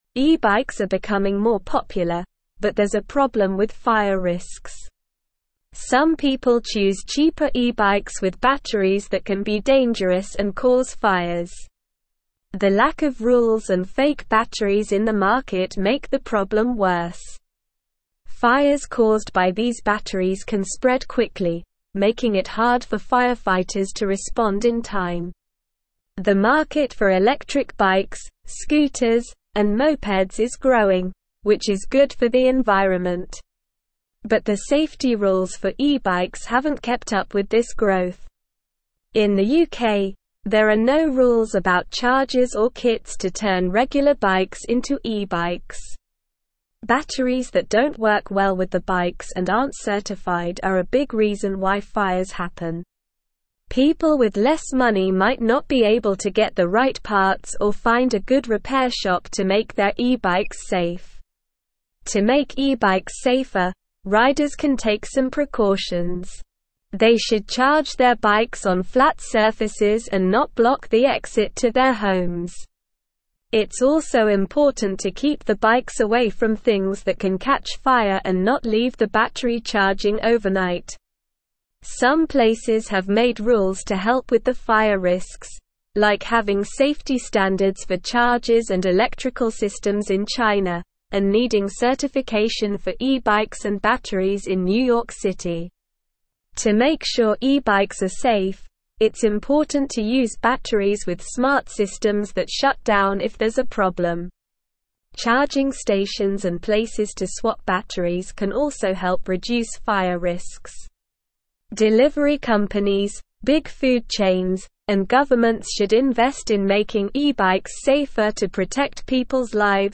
Slow
English-Newsroom-Upper-Intermediate-SLOW-Reading-Risks-and-Regulations-E-Bike-Safety-Concerns-and-Solutions.mp3